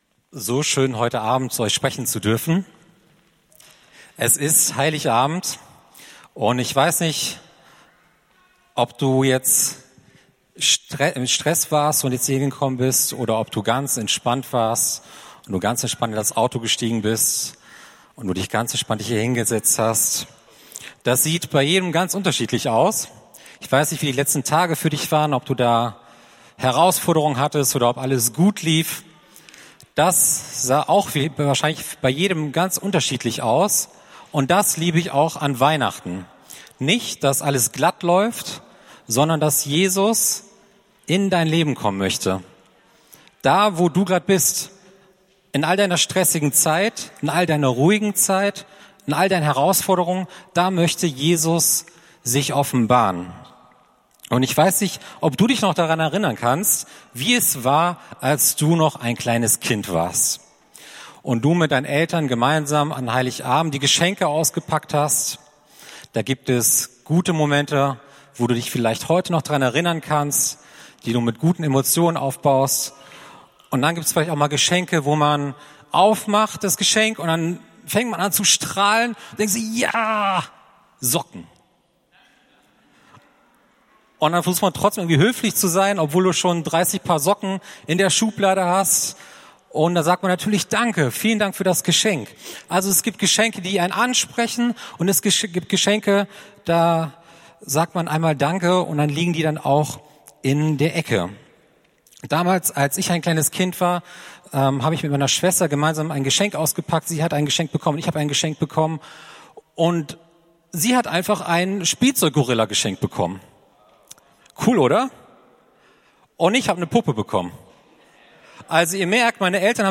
Heiligabend-Gottesdienst